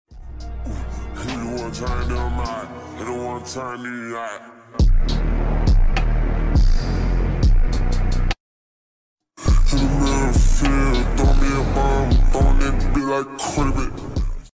💙 Nissan Skyline GTR R34 sound effects free download